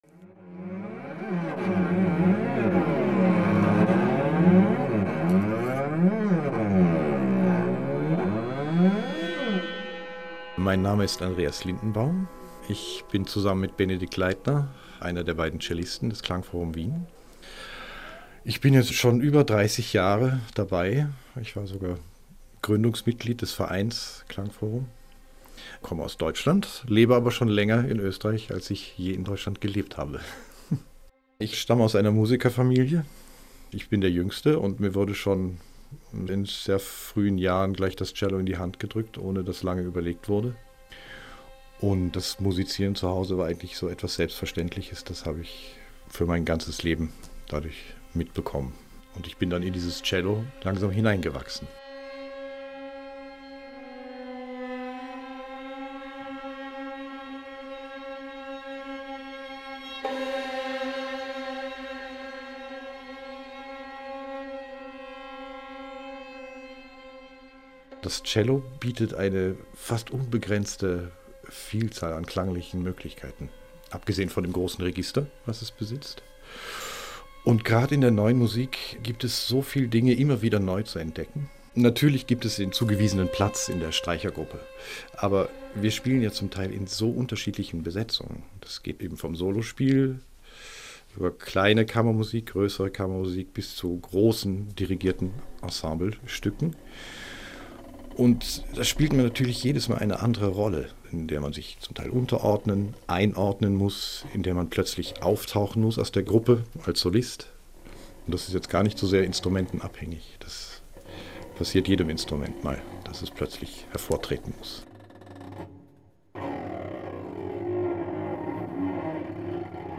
Ö1 Audio-Porträt https